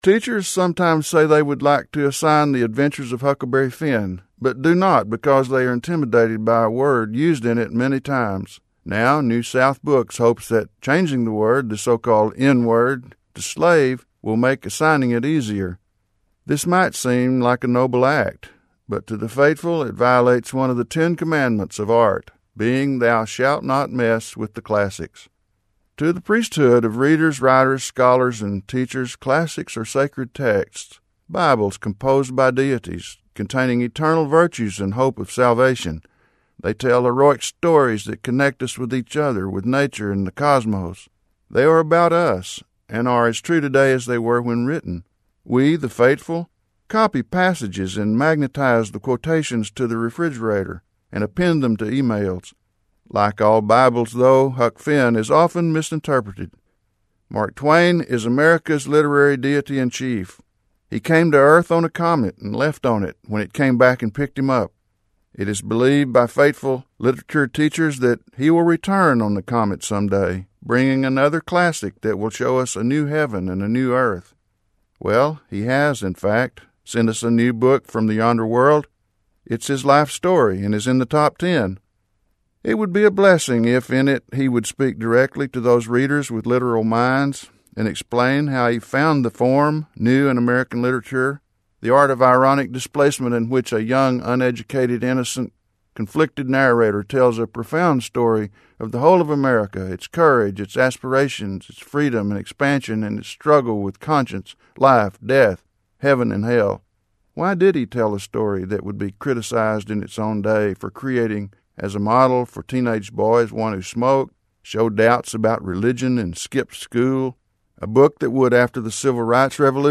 • KERA radio commentary: